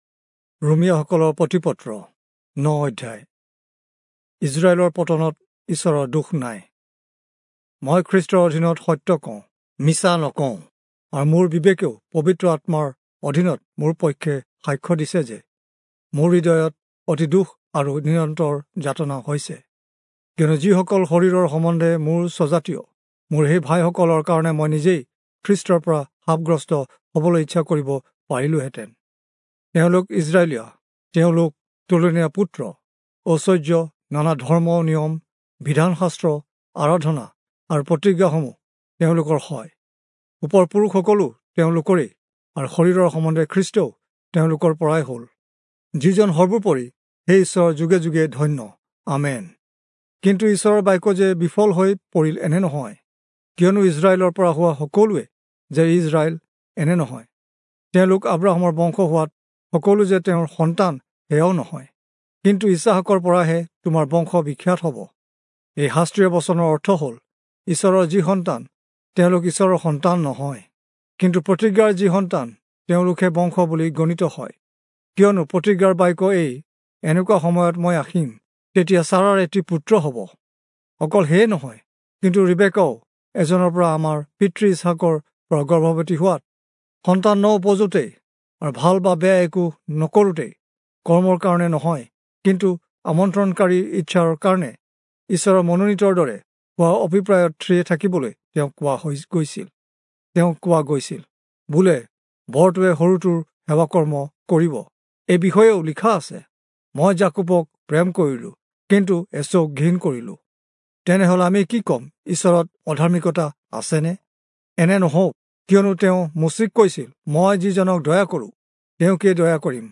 Assamese Audio Bible - Romans 5 in Kjv bible version